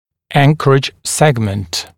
[‘æŋkərɪʤ ‘segmənt] [rɪ’æktɪv ‘segmənt][‘энкэридж ‘сэгмэнт] [ри’эктив ‘сэгмэнт]опорный участок ( при анкеровке)